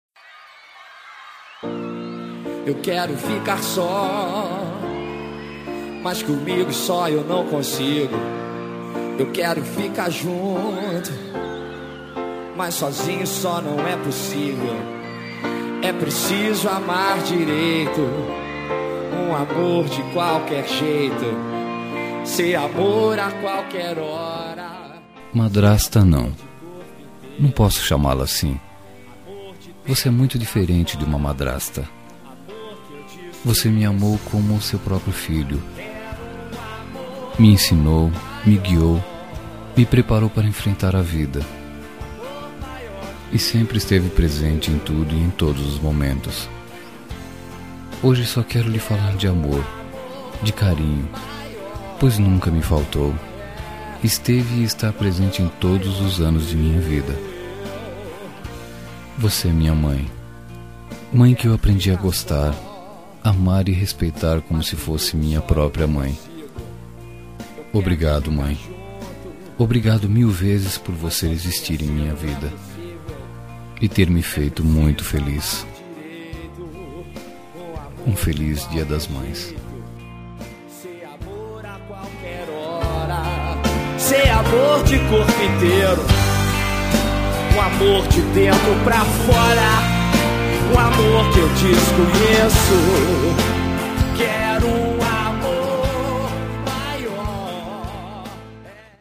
Dia das Mães – Consideração Madrasta – Voz Feminina – Cód: 06709